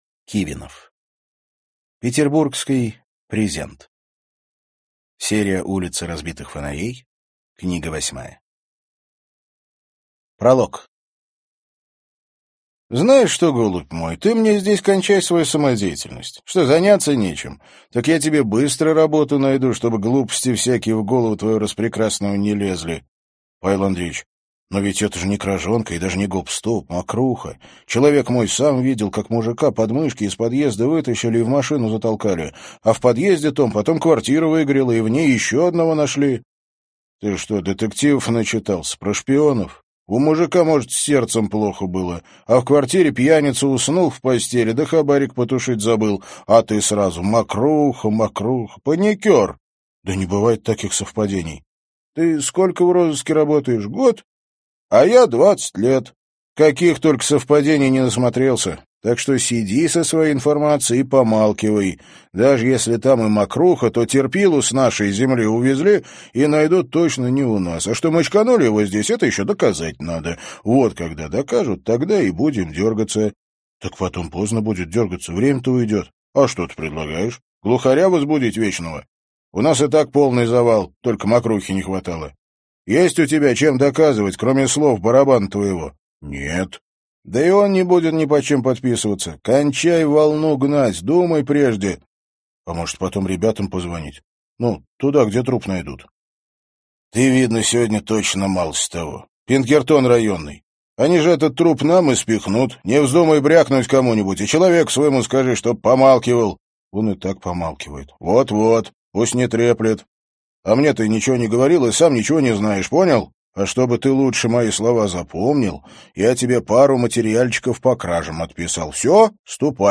Аудиокнига Петербургский презент | Библиотека аудиокниг